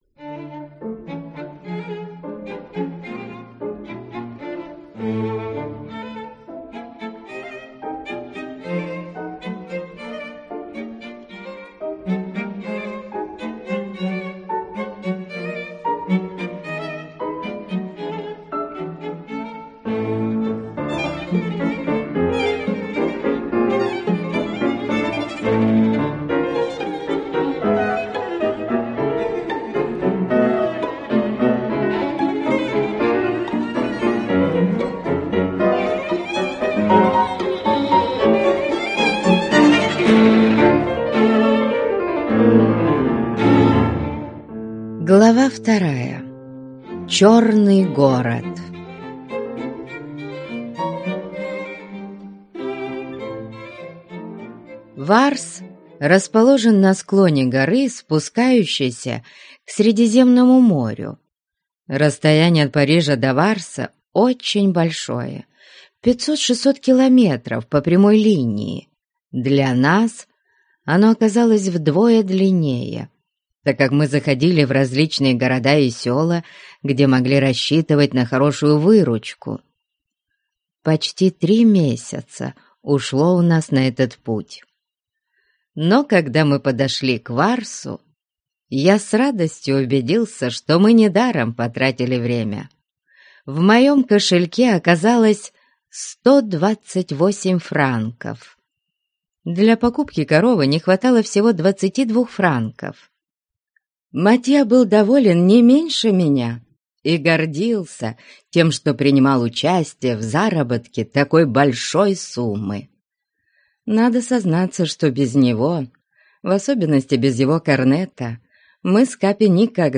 Аудиокнига Без семьи | Библиотека аудиокниг